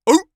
seal_walrus_bark_single_02.wav